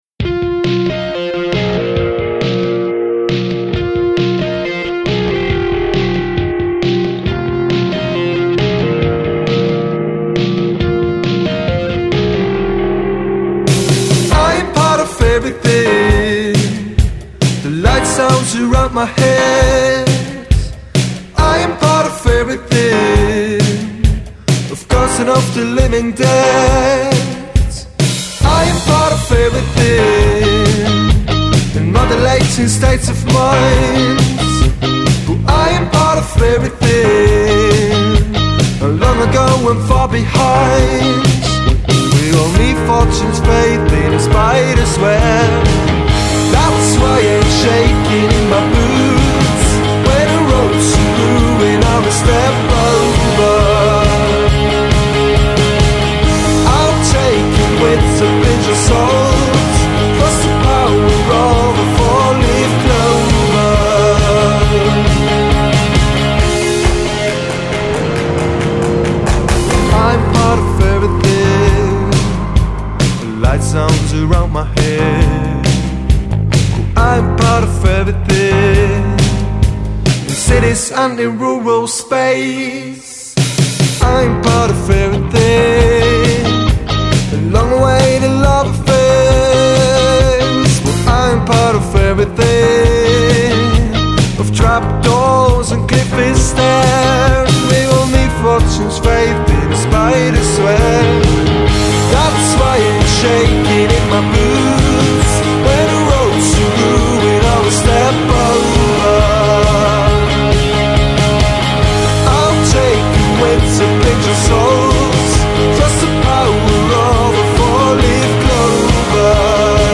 Pop.